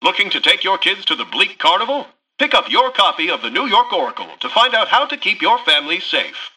Newscaster_headline_48.mp3